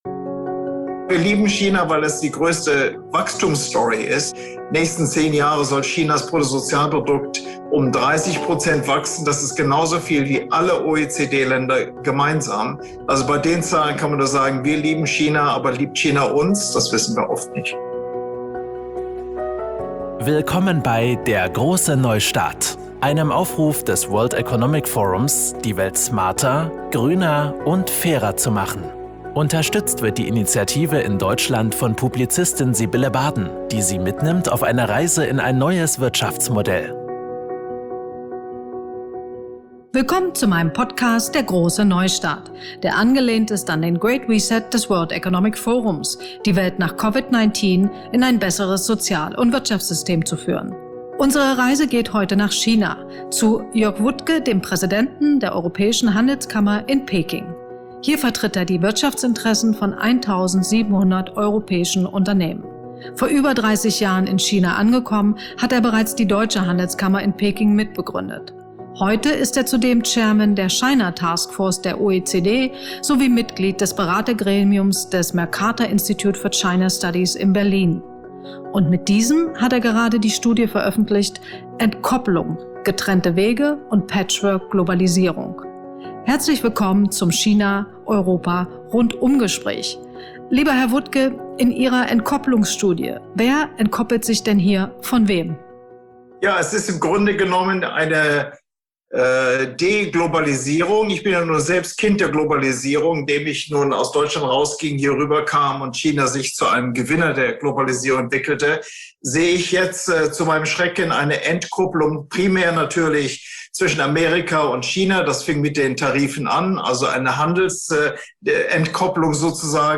Ein Rundumgespräch über europäisch-chinesische Wirtschaftsinteressen.